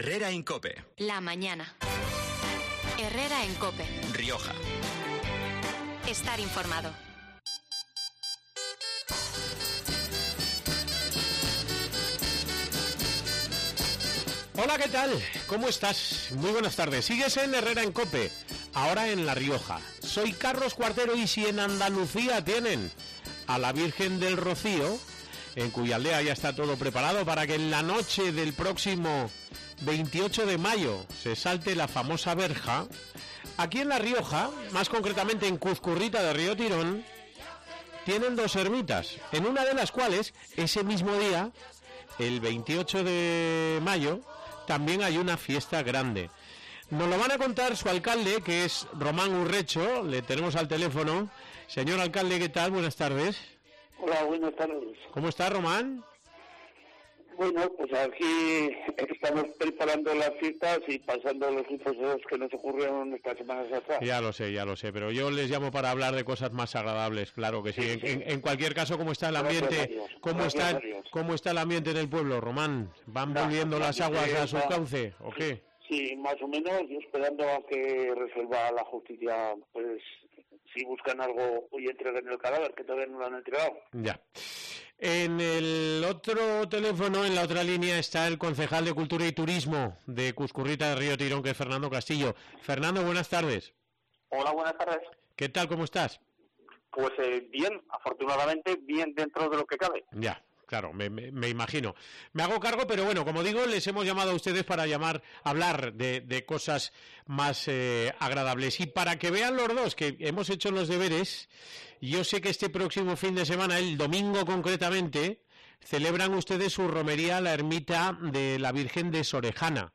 El alcalde de Cuzcurrita, Román Urrecho, y el concejal de Cultura y Turismo, Fernando Castillo, ofrecen detalles sobre sus próximas romerías de...